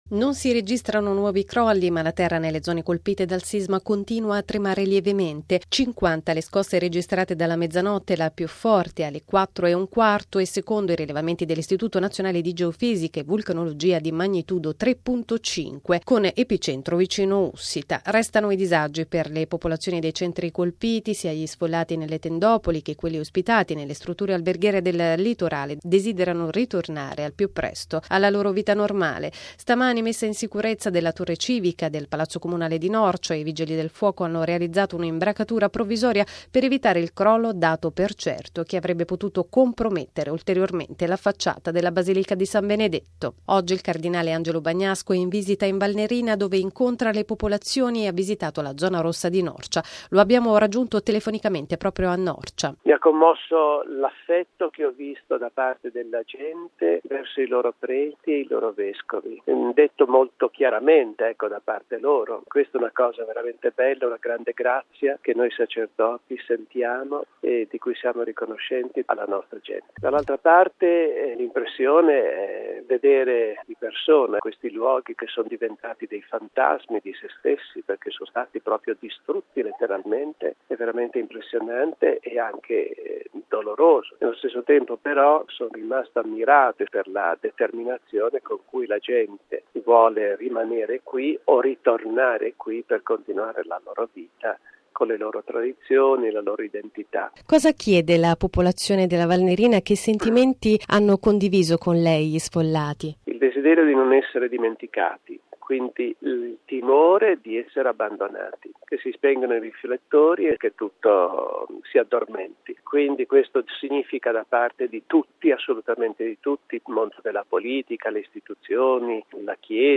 Lo abbiamo raggiunto telefonicamente: